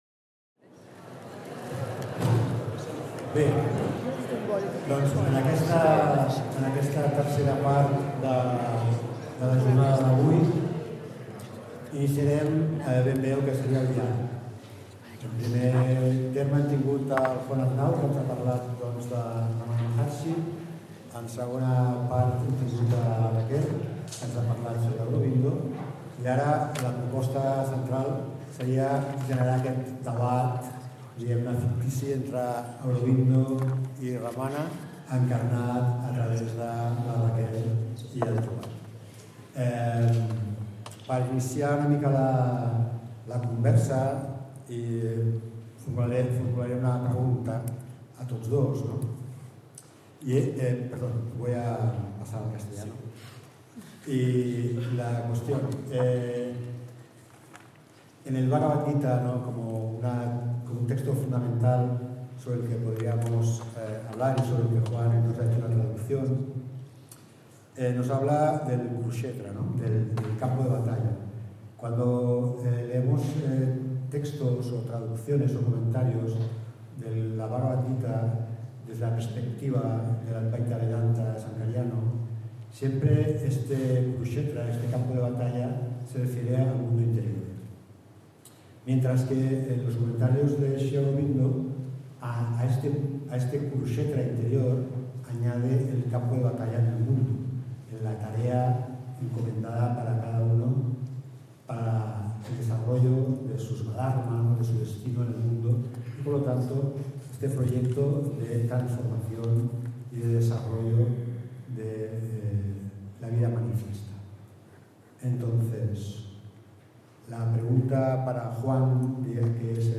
Els dos especialistes debaten al voltant de la polaritat que coexisteix dins l'escola no dualista (Advaita Vedanta), encarnada en dues de les personalitats més influents de l'espiritualitat contemporània, Ramana Maharshi (representant de la tradició Advaita Vedanta Mayavadai) i Sri Aurobindo (màxim exponent del Purna Advaita vedanta)  Aquest document està subjecte a una llicència Creative Commons: Reconeixement – No comercial – Compartir igual (by-nc-sa) Mostra el registre complet de l'element